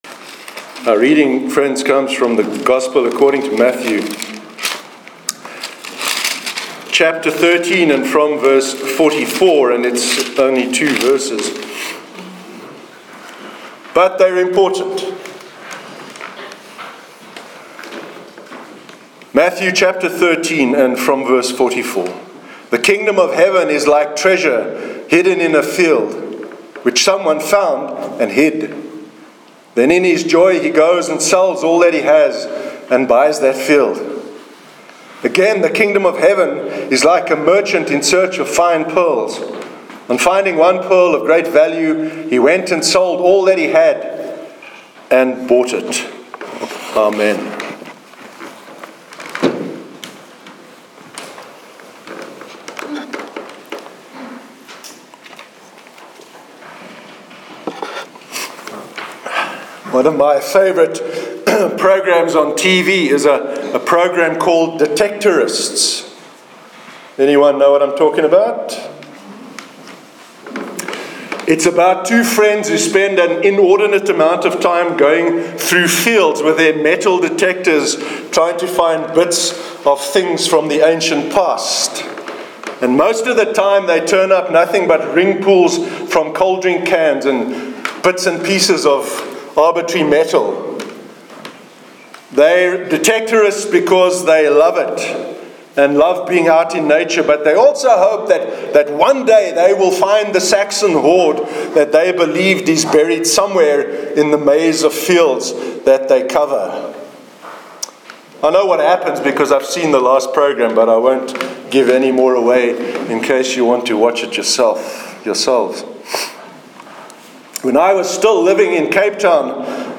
Sermon on the Parable of the Pearl of Great Value- 10th September 2017